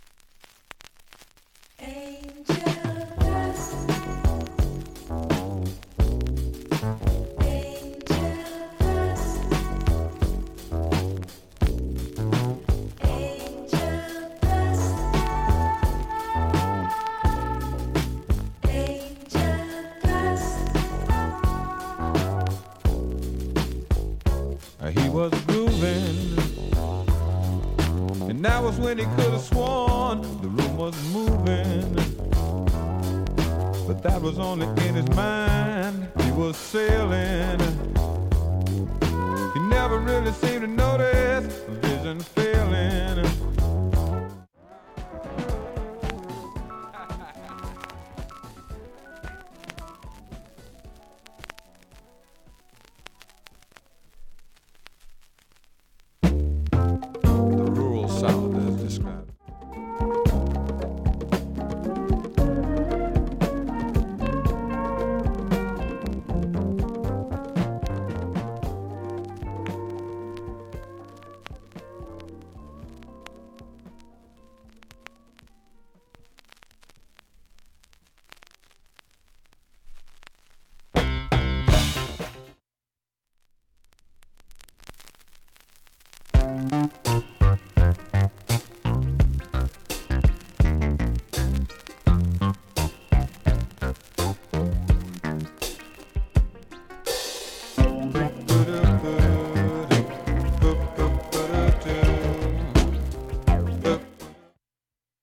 盤面もきれいです音質良好全曲試聴済み。
３回までのかすかなプツが２箇所
単発のかすかなプツが５箇所